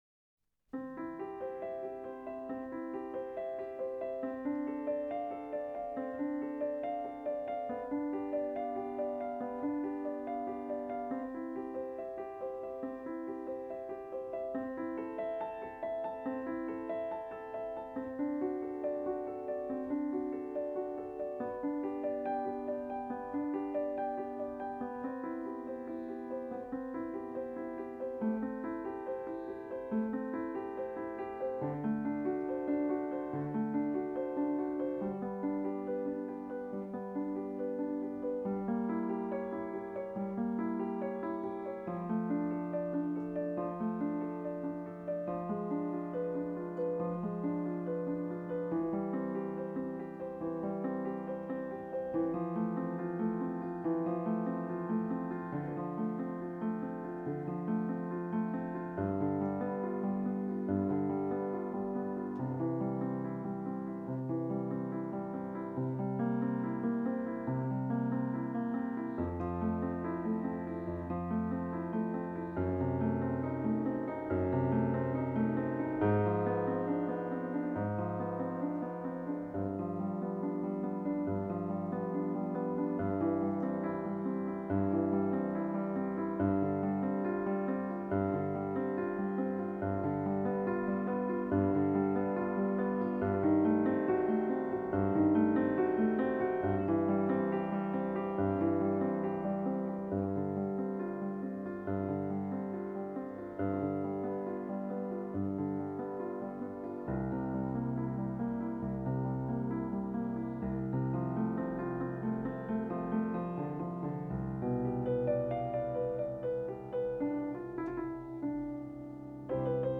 作为一个擅长演奏巴赫作品的钢琴家，他展现出清晰、空灵的显著风格，个性十足，并且获得了极大的成功。
来实现音乐的表现力，具有冼练、明澈的效果，也很庄重、和谐。